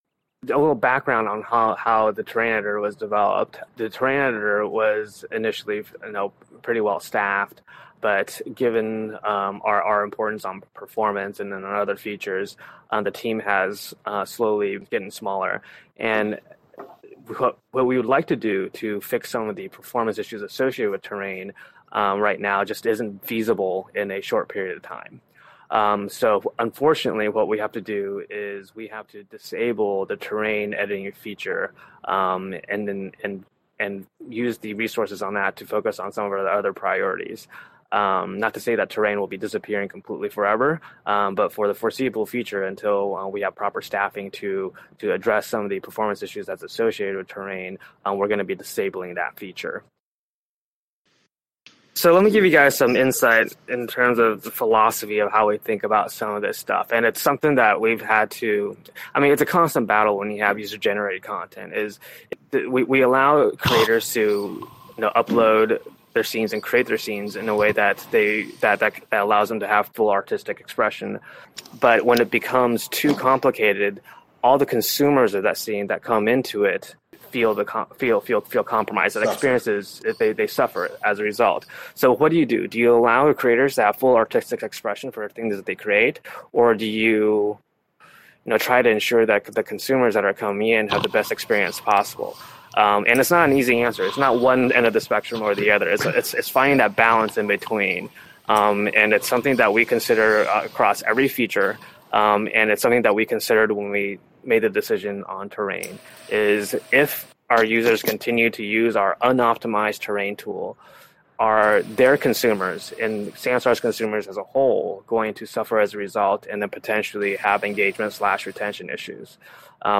These weekly Product Meetings are open to anyone to attend, are a mix of voice (primarily) and text chat.